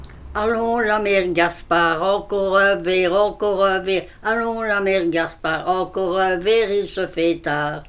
Chanson incomplète.
Genre : chant Type : chanson à boire
Lieu d'enregistrement : Ans